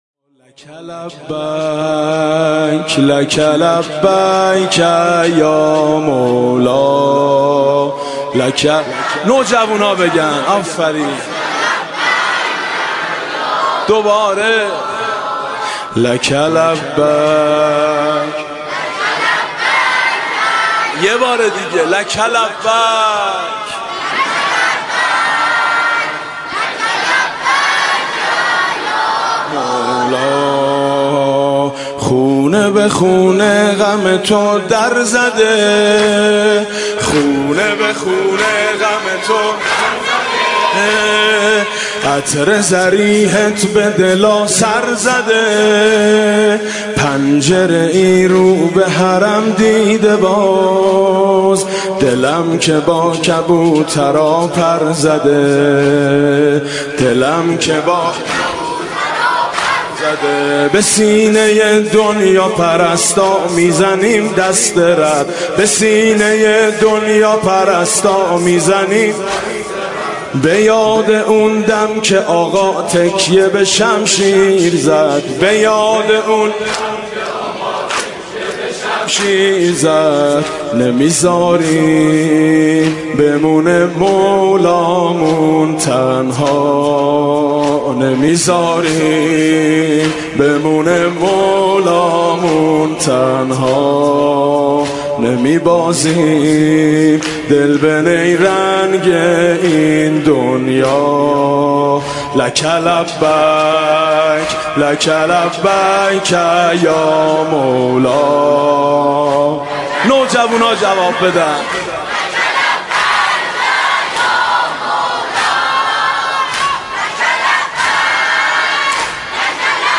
نوحه جديد میثم مطیعی, مداحی محرم میثم مطیعی